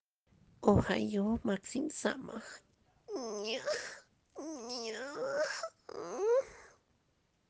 Flow: What Text: The Soundqualität: Fuck? Allgemeines: Du bekommst den Punkt, weil dein Stöhner geil …